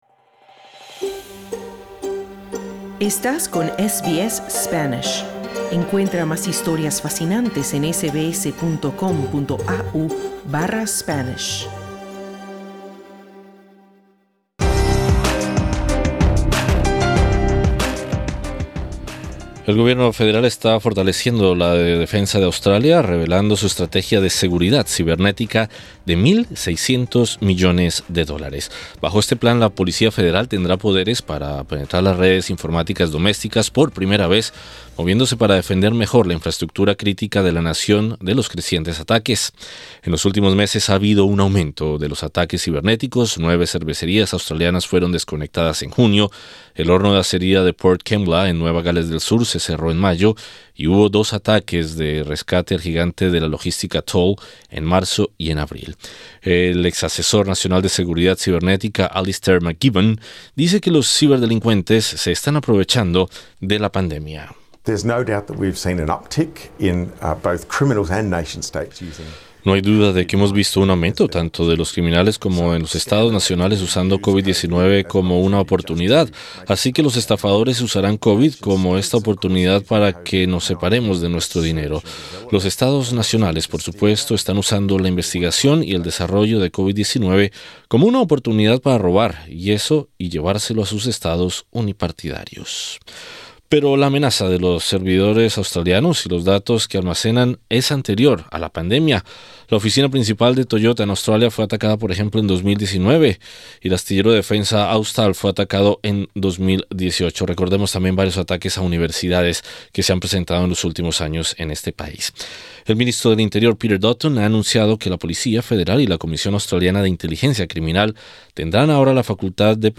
Sobre el anuncio, conversamos con el experto en estrategia y seguridad en Canberra